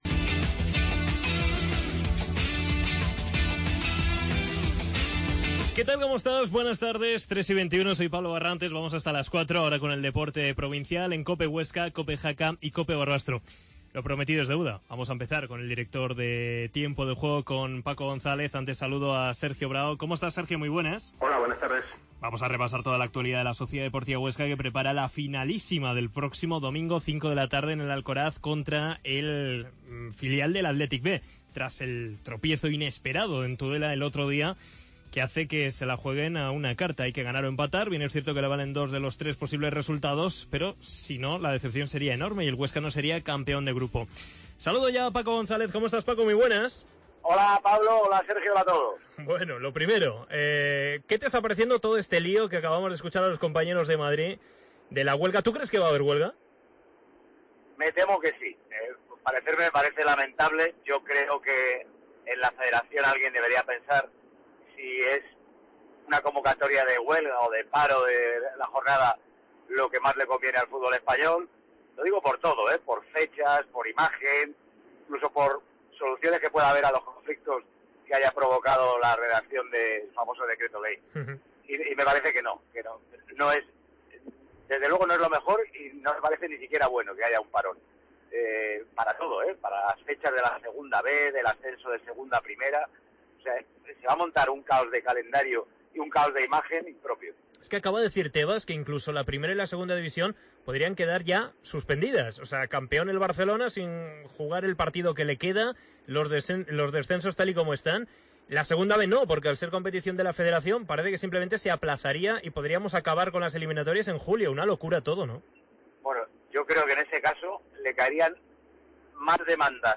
Entrevista al director de Tiempo de Juego Paco González